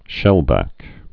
(shĕlbăk)